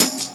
Track 02 - Percussion OS 01.wav